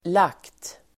Uttal: [lak:t]